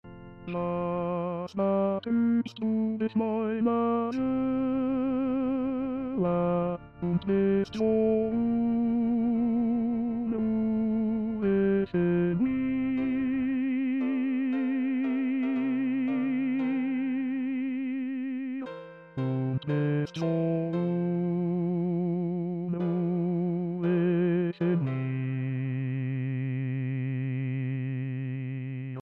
Introduction 5 voix, mes.  1-13